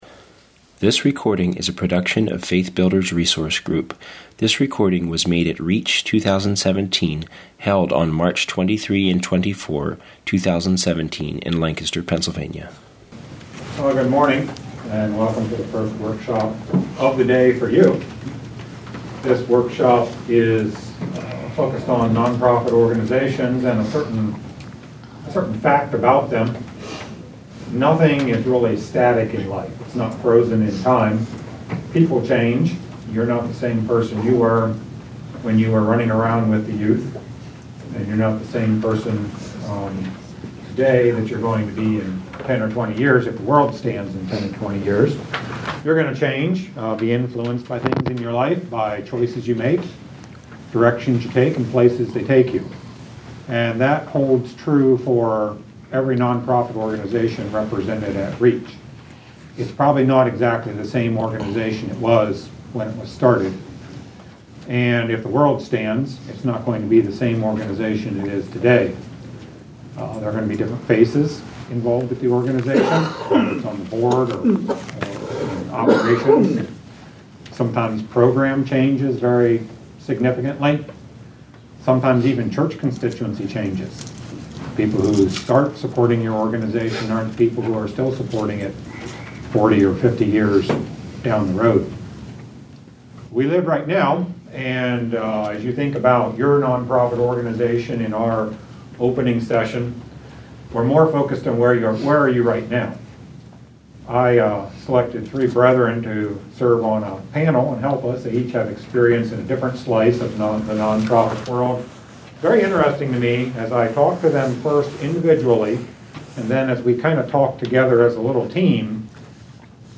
Course Correction Panel Discussion
6258-course-correction-panel-discussion.mp3